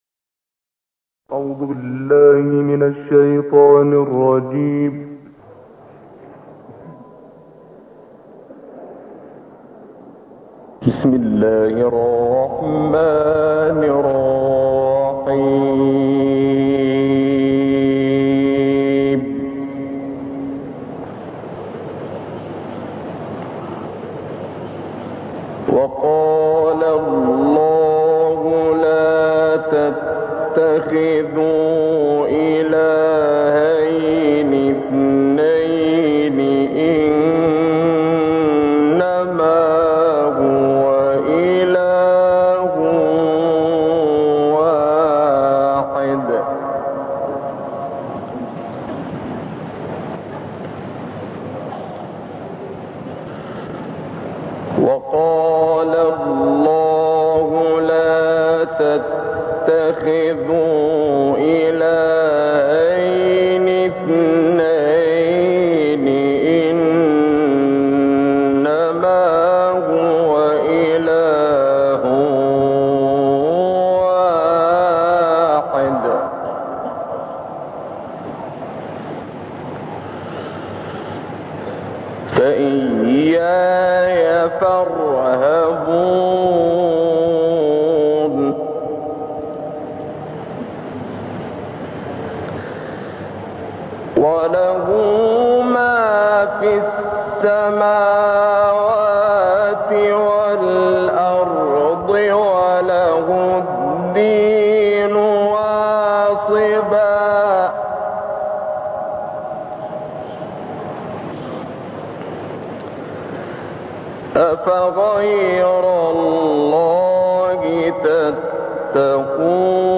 تلاوتی زیبا از استاد شعبان عبدالعزیز الصیاد ... آیات 51 تا 74 سوره نحل